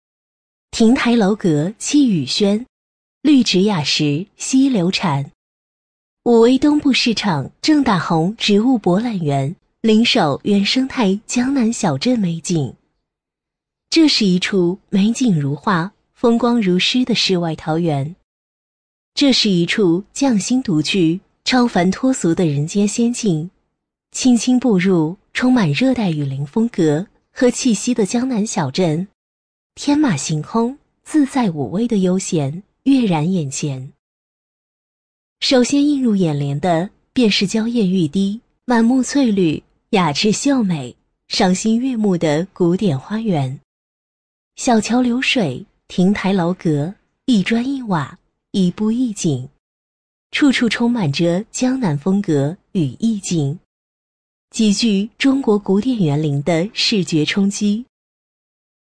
【女50号专题】沉稳-武威
【女50号专题】沉稳-武威.mp3